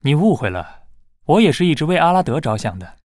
文件 文件历史 文件用途 全域文件用途 Bhan_amb_02.ogg （Ogg Vorbis声音文件，长度3.0秒，106 kbps，文件大小：39 KB） 源地址:游戏中的语音 文件历史 点击某个日期/时间查看对应时刻的文件。